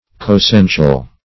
Meaning of coessential. coessential synonyms, pronunciation, spelling and more from Free Dictionary.
Search Result for " coessential" : The Collaborative International Dictionary of English v.0.48: Coessential \Co`es*sen"tial\, a. Partaking of the same essence.
coessential.mp3